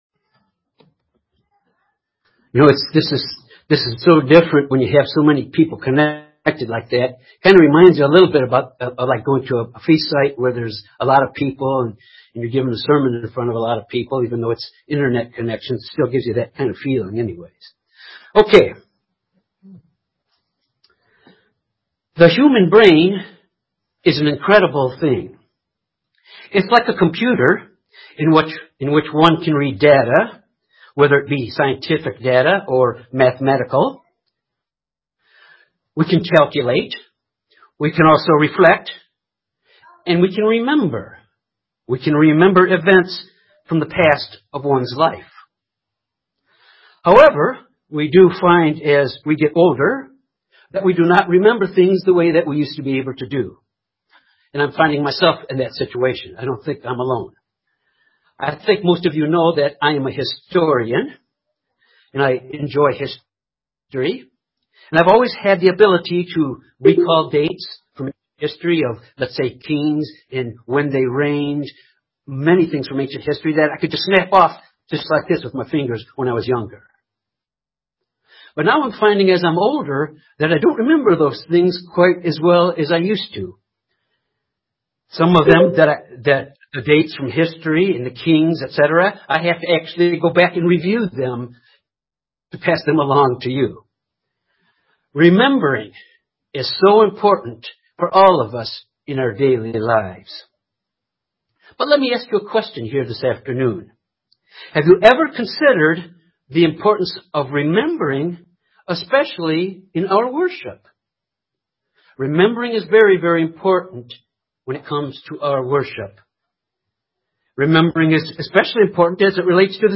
This sermon examines the significance of the Passover ceremony.